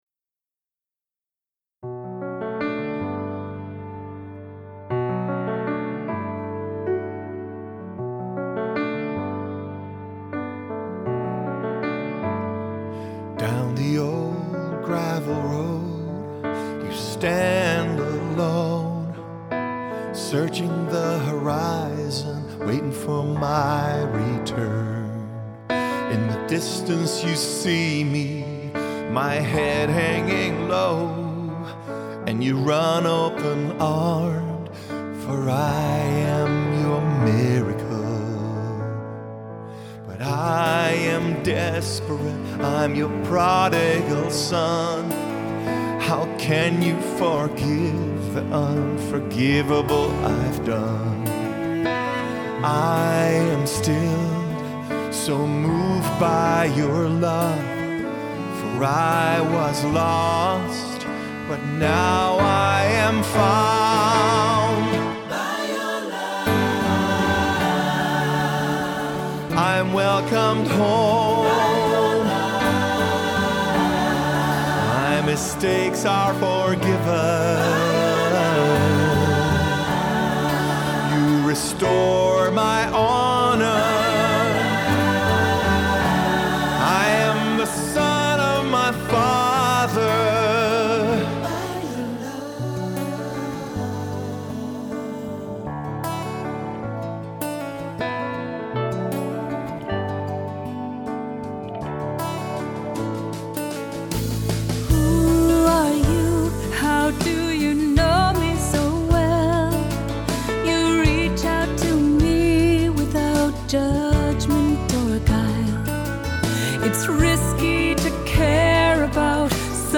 Accompaniment:      Keyboard
Music Category:      Christian
Solo verses provide opportunities for a few singers.